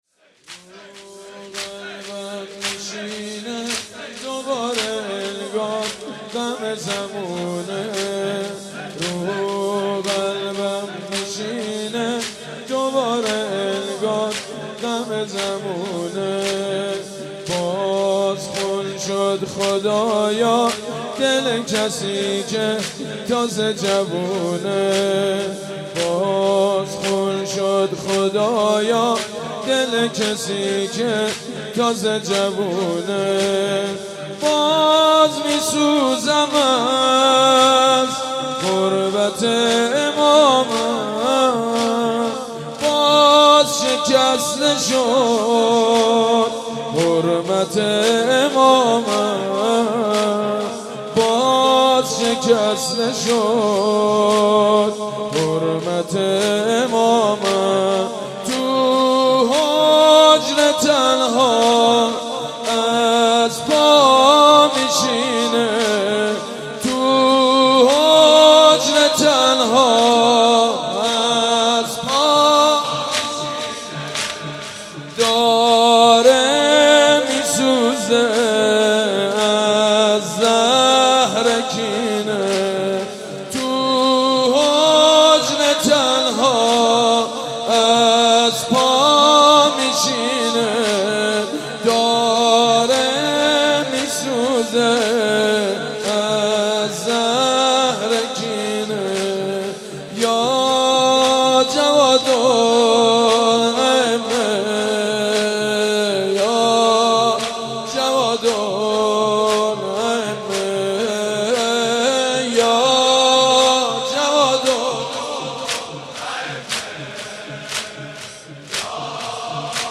«شهادت امام جواد 1393» زمینه: رو قلبم میشینه دوباره انگار غم زمونه